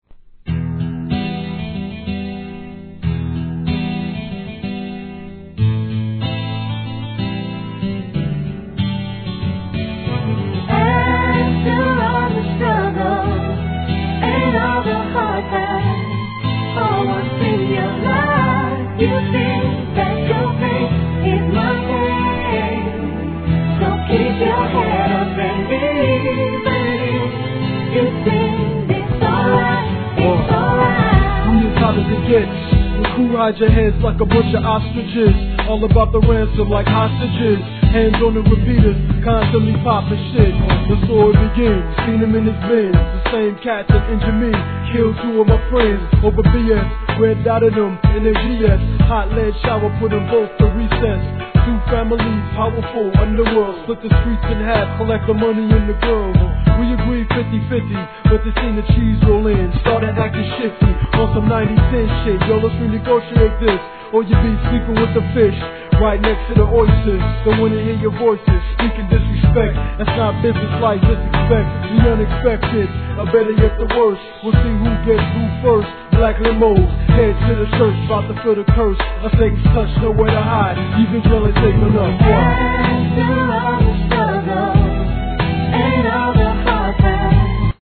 HIP HOP/R&B
アコースティックの哀愁ナンバー！